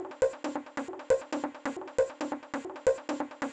• synth techno bleep sequence club.wav
synth_techno_bleep_sequence_club_f4d.wav